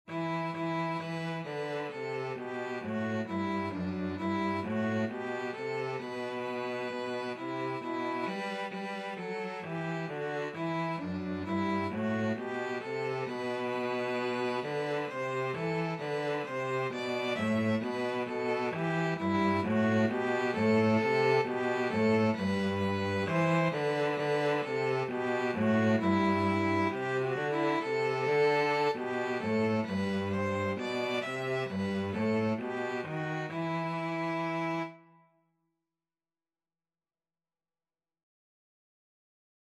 Free Sheet music for Violin-Cello Duet
The melody is in the minor mode.
Slow two in a bar feel = c. 66
E minor (Sounding Pitch) (View more E minor Music for Violin-Cello Duet )
4/4 (View more 4/4 Music)
Traditional (View more Traditional Violin-Cello Duet Music)